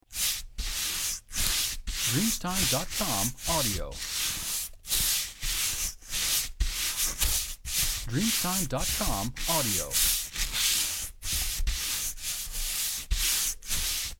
Brushing Fast 3
• SFX